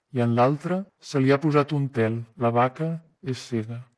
男性讲话声音的转变